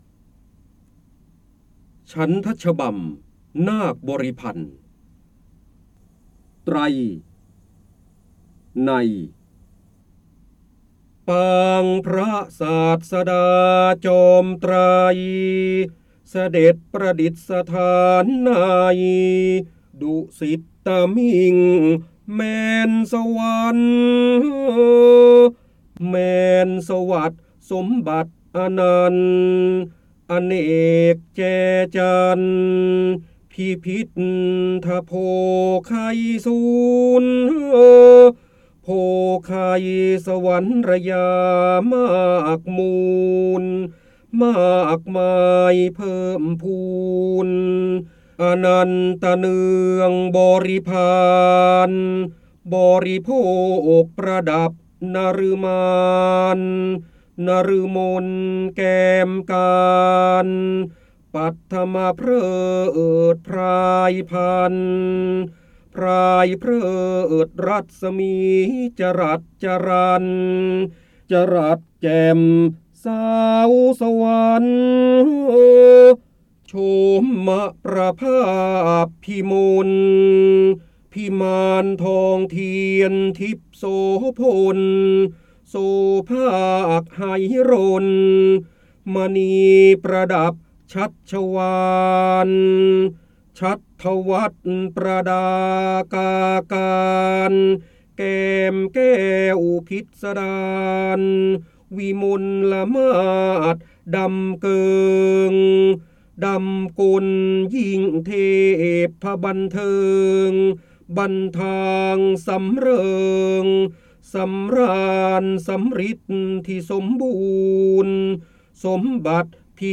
เสียงบรรยายจากหนังสือ จินดามณี (พระโหราธิบดี) ฉันทฉบำนาคบริพันท์ฯ
คำสำคัญ : พระเจ้าบรมโกศ, ร้อยแก้ว, พระโหราธิบดี, ร้อยกรอง, จินดามณี, การอ่านออกเสียง